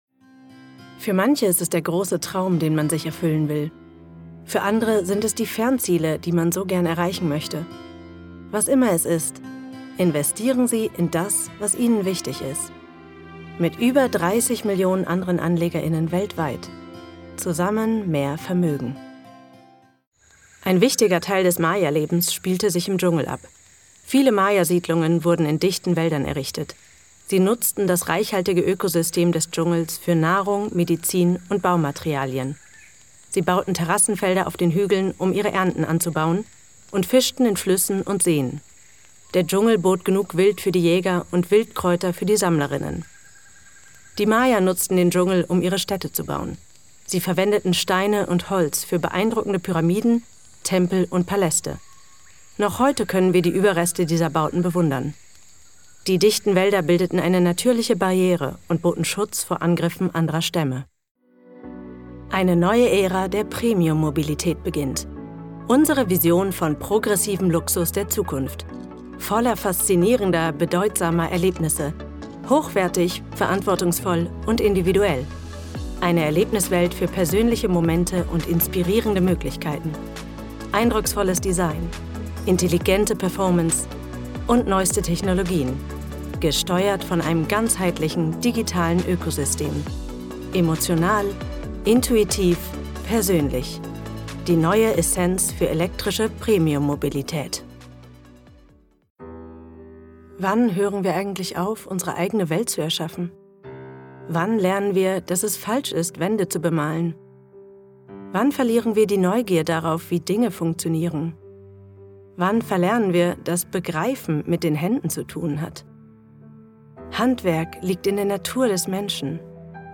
VOICE REEL
German Actress, highly proficient in English, French and Italian.